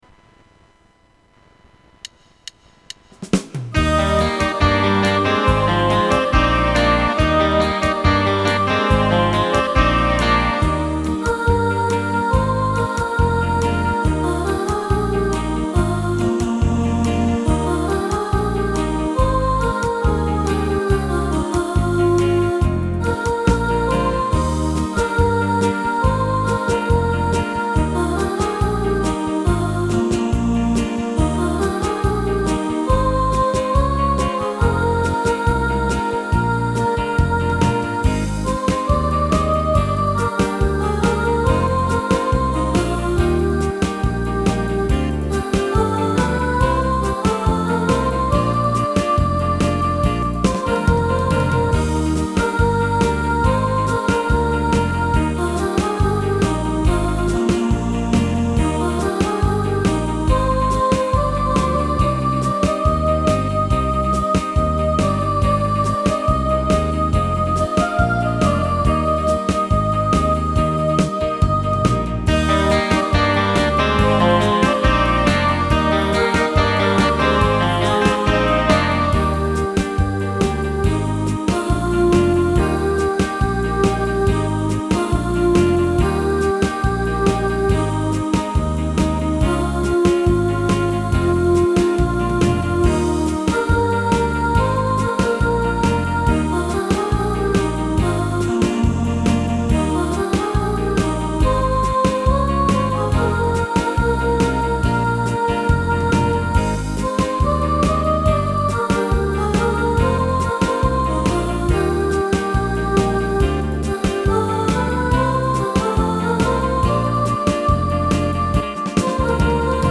Georgy Girl (Soprano) | Ipswich Hospital Community Choir